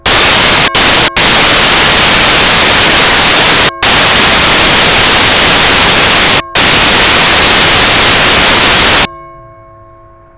Single Church Bell (ding) Ref
SINGLE_churchbell.wav